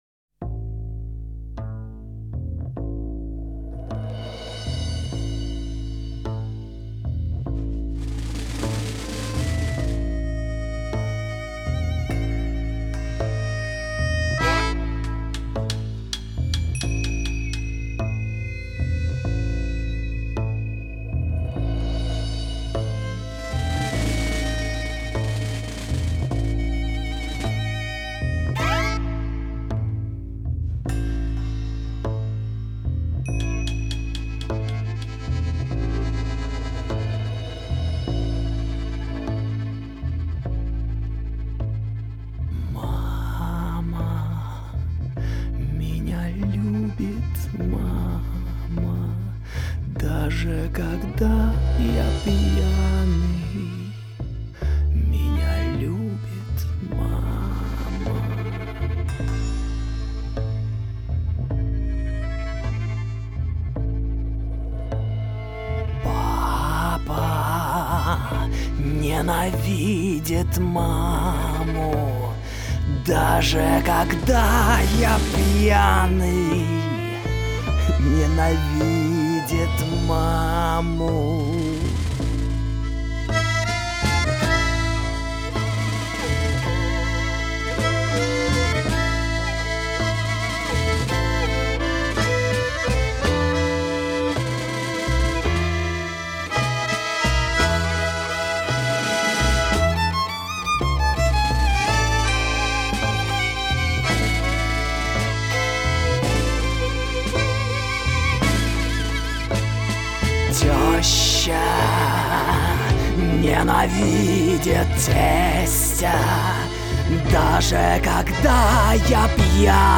контрабас, вокал.
аккордеон, вокал.
скрипка, вокал.
барабаны, перкуссия.